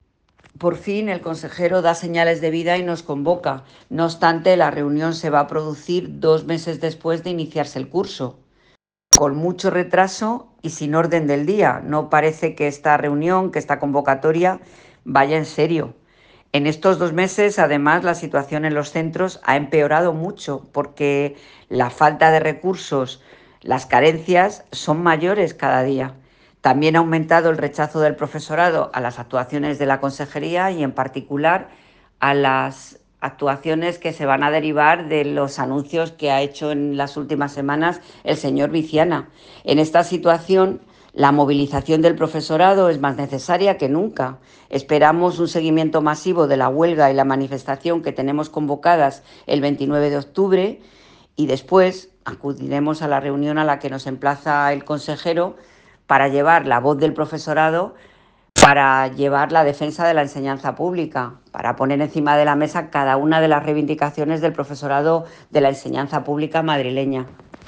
Nota de voz